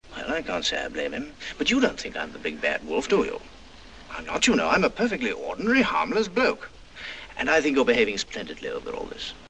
Apprezzato cantante, con una bella voce baritonale.